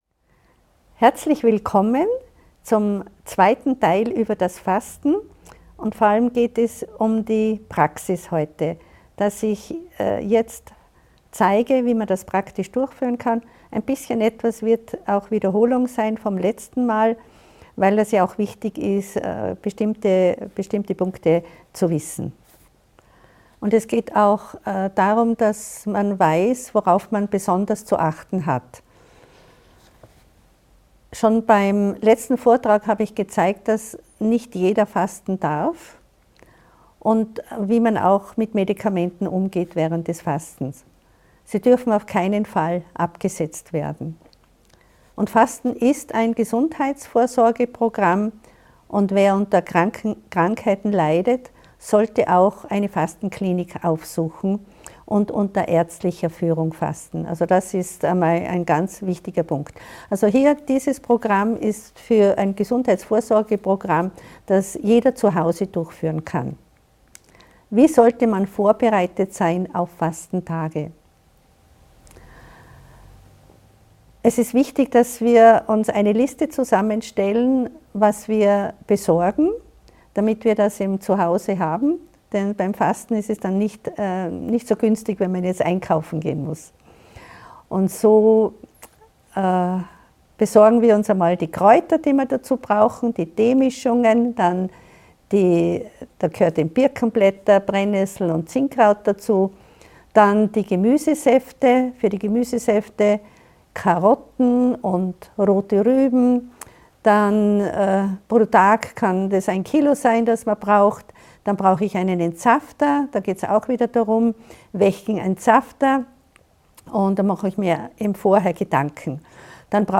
Entdecken Sie die transformative Kraft des Fastens in diesem aufschlussreichen Vortrag! Von der richtigen Vorbereitung bis hin zur idealen Nachsorge wird der gesamte Prozess erklärt. Praktische Tipps und wichtige Hinweise helfen, Fasten sicher durchzuführen.